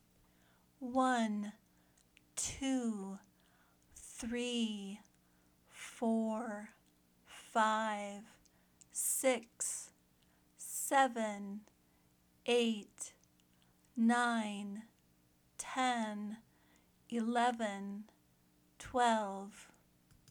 Listen to the audio to hear how these words are pronounced.
Pronounce Cardinal Numbers